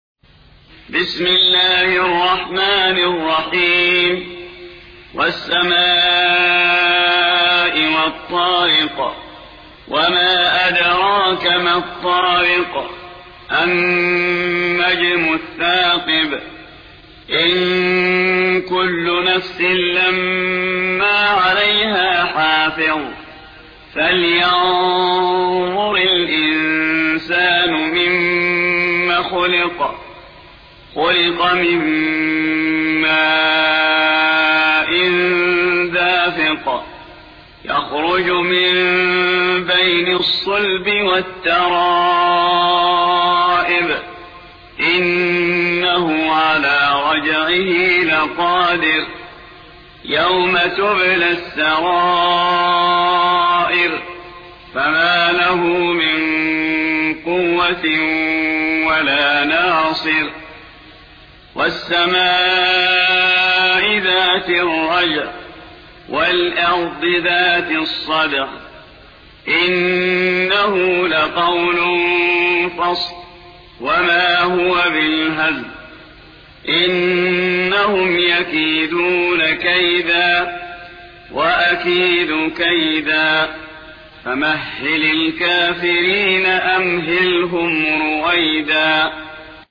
86. سورة الطارق / القارئ